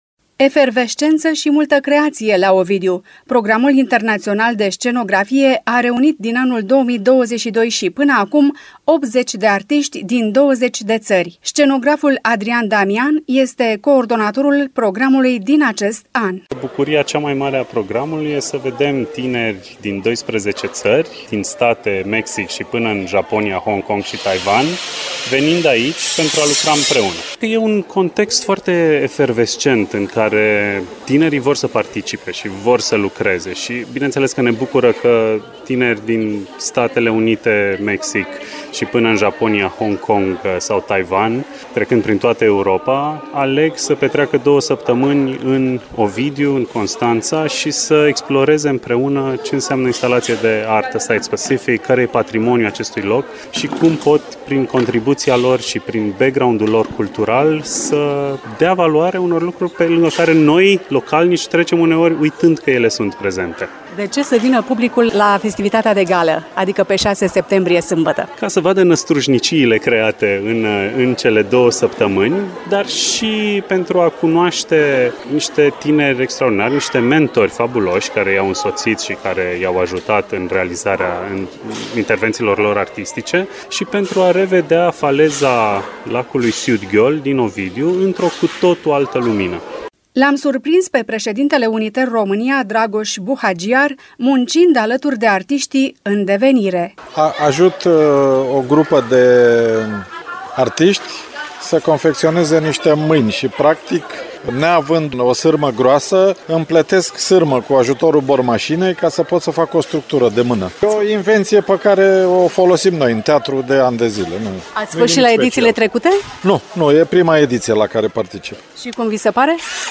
Urmează un reportaj